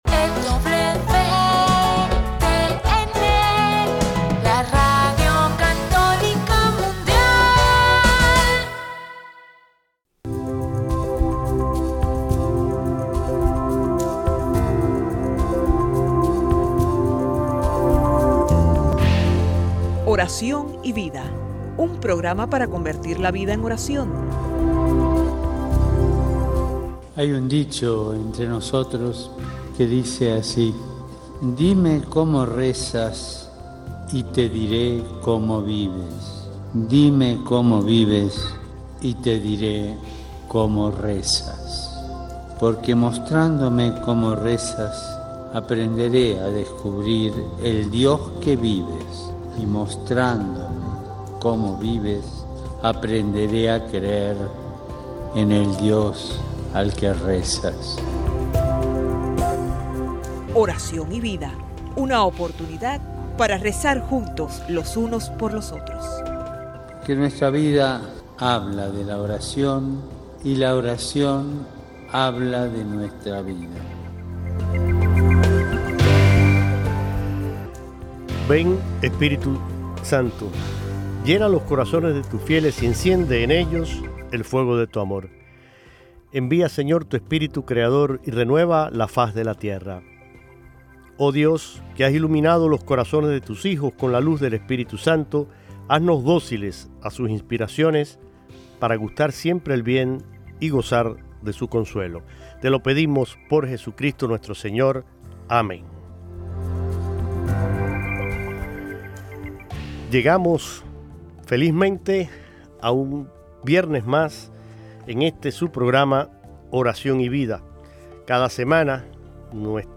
comparte en vivo cada lunes con sus invitados una variedad de temas que ofrecen a los radioescuchas los elementos necesarios para crecer en la formación humana y cristiana.